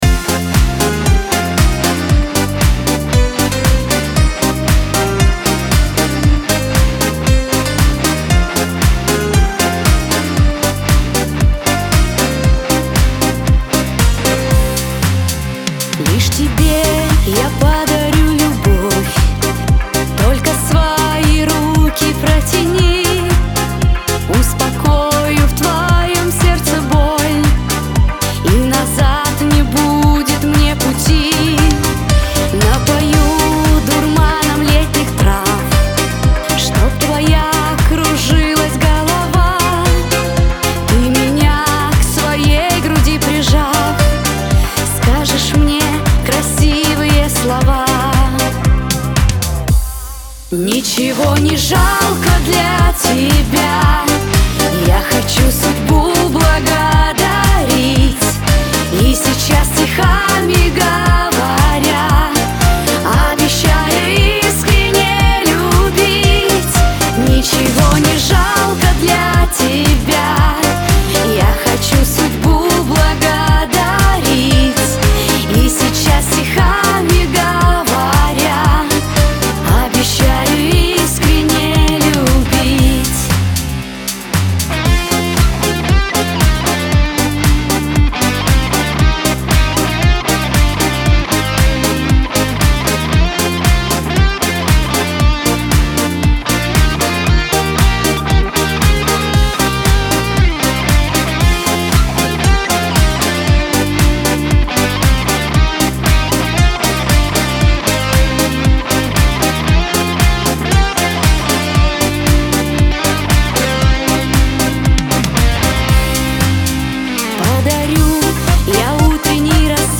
эстрада
диско
pop